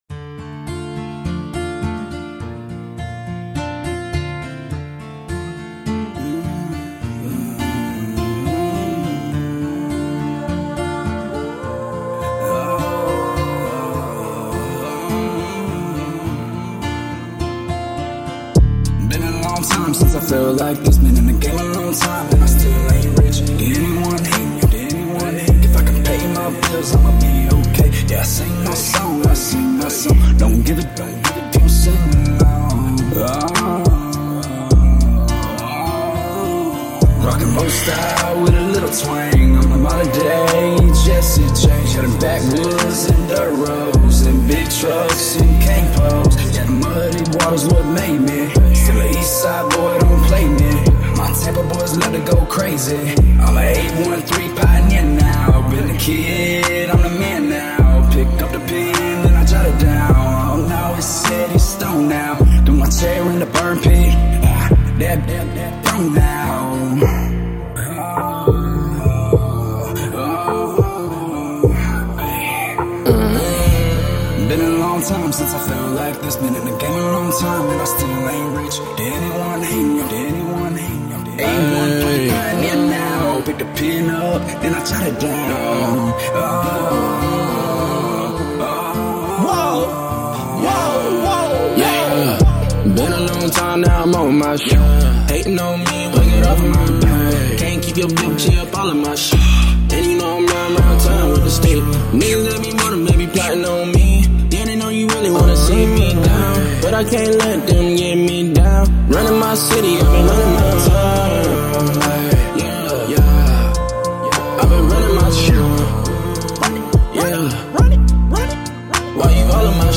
American Country Hip-Hop Rocker